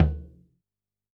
Index of /musicradar/Kit 14 - Acoustic
CYCdh_K5-Tom03a.wav